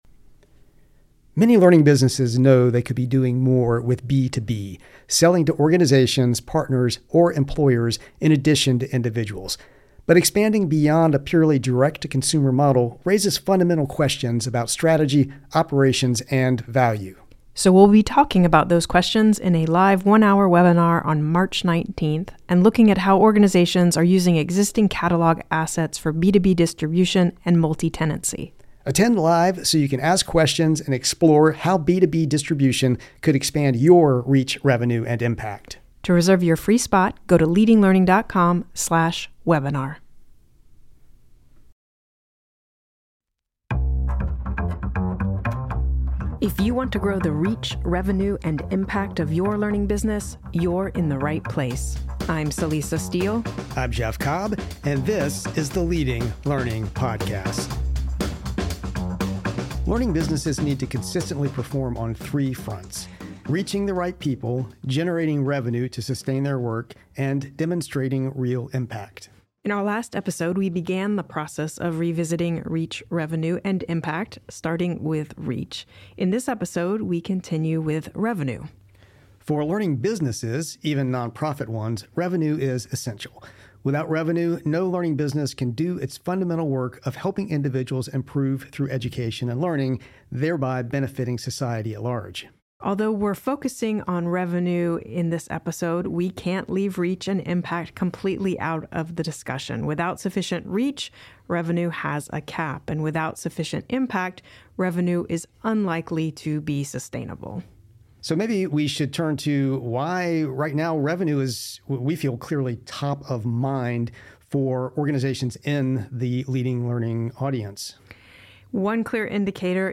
If you’re unsure which offerings are truly pulling their weight, whether you’re leaving money on the table, or how to decide what to keep, cut, or redesign, this conversation can help.